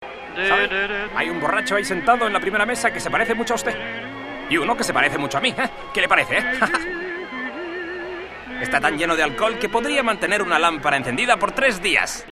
También monoaural, el ruido de fondo en este caso no resulta molesto y la fidelidad y calidad de los diálogos es lo que le corresponde por su antigüedad.
Por increíble que parezca, la frase "ofensiva" es "Está tan lleno de alcohol que podría mantener una lámpara encendida tres días". Este fragmento sonoro ha sido reemplazado ahora por una voz que no se parece en nada a la del resto del doblaje y que nos parece bastante ridícula.
Como ya se ha comentado, hay un corto fragmento redoblado en la pista castellana con una voz que resulta bastante ridícula por comparación con el resto del audio castellano.